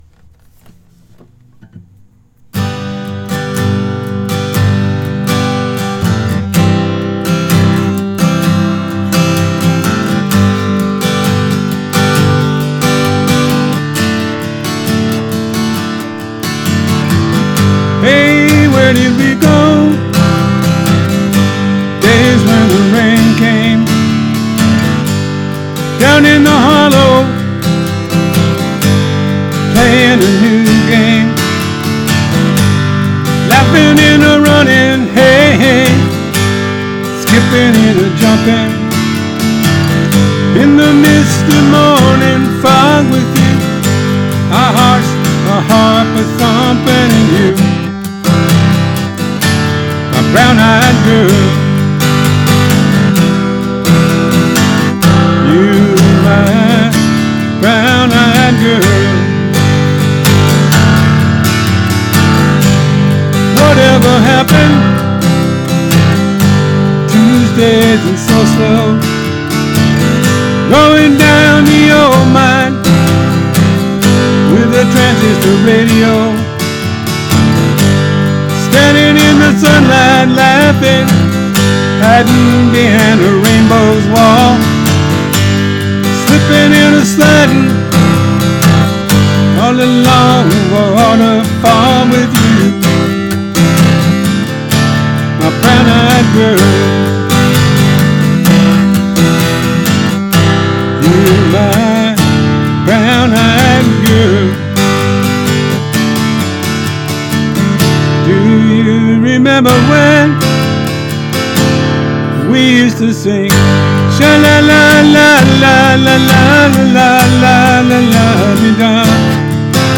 Here’s my cover.